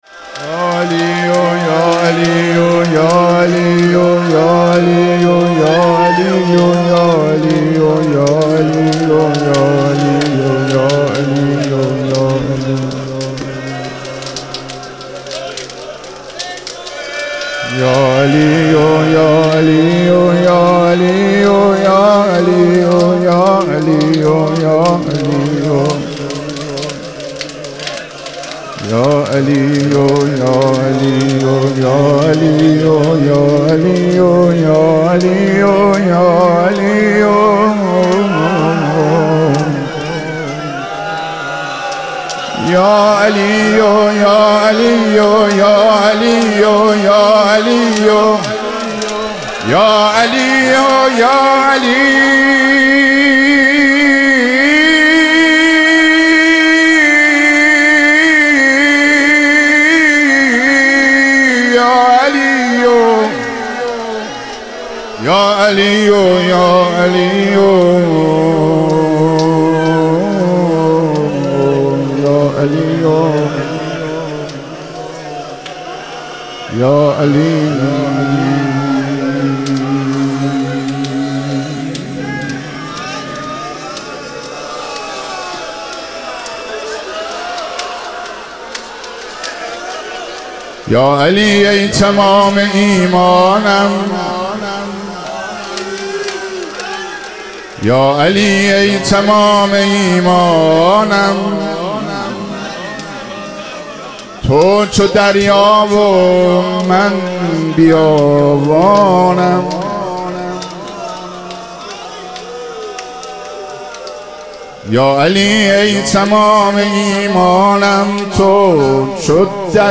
ایام فاطمیه اول - مدح و رجز